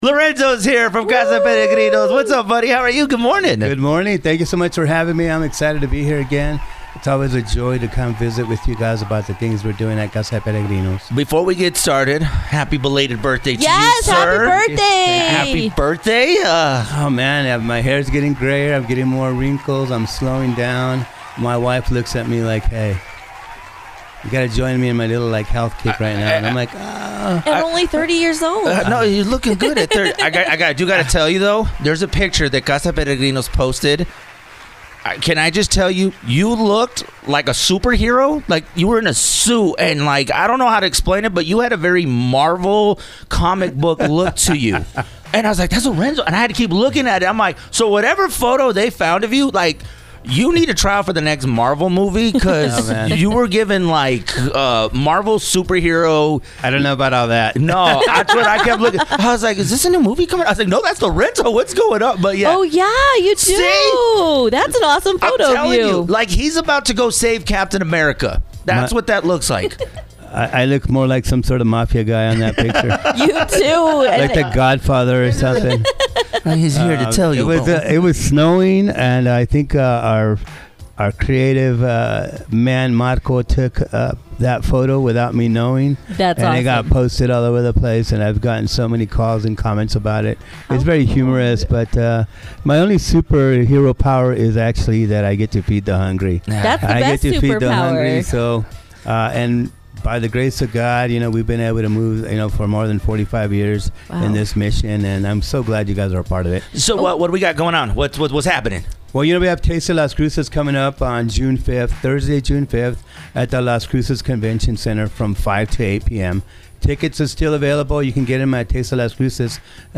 thetasteoflcinterview.mp3